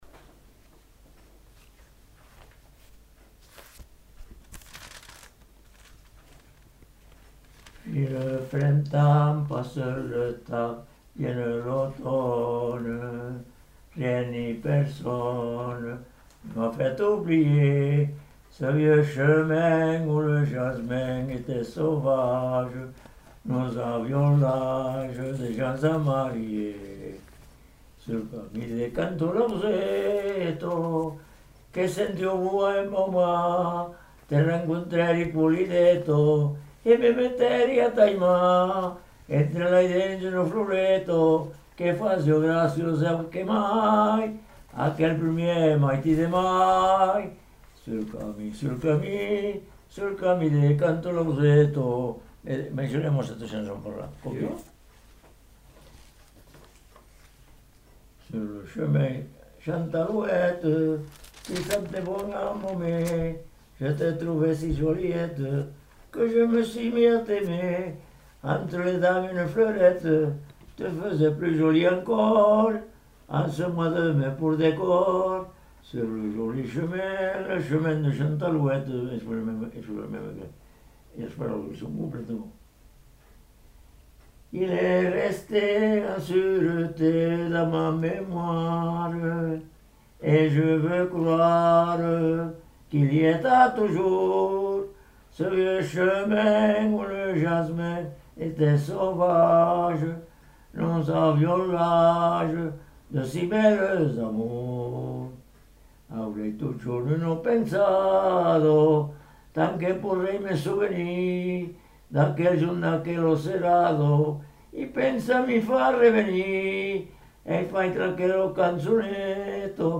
Aire culturelle : Lauragais
Lieu : Caraman
Genre : chant
Effectif : 1
Type de voix : voix d'homme
Production du son : chanté